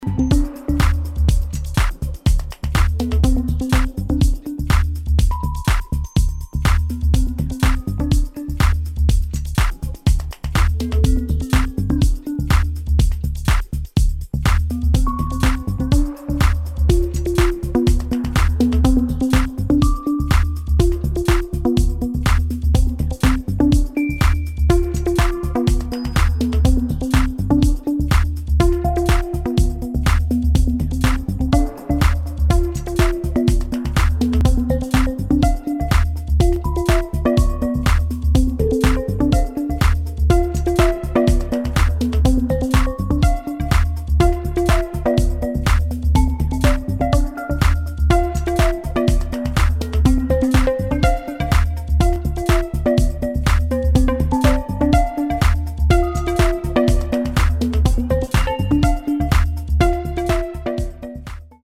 [ DEEP HOUSE | TECH HOUSE ]